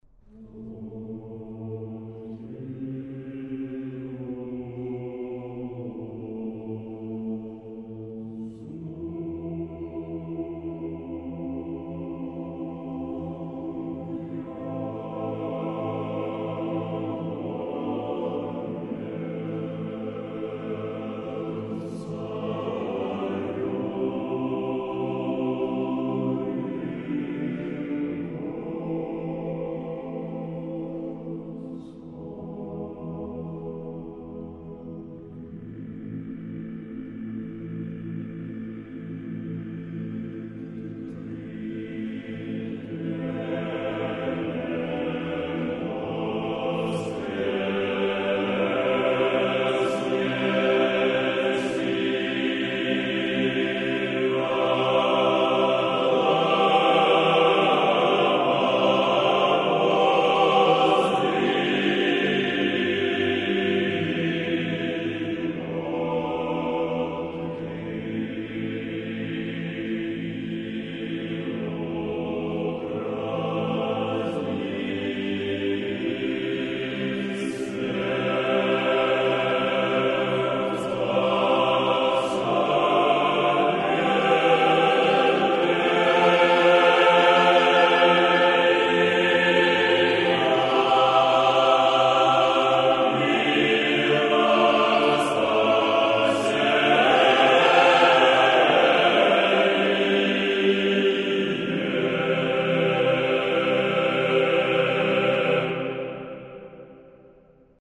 After the ninth canon comes the exapostalarion, Having slept in the flesh… , sung three times:
Exapostilarion, Tone 3: